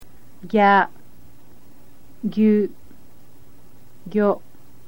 click on any of a group to hear the group spoken